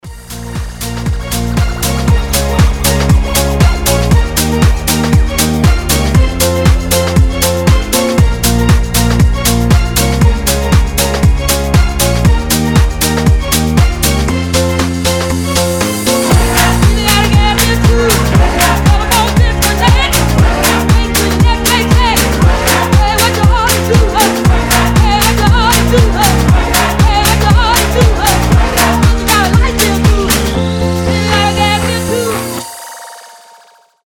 Госпел хаус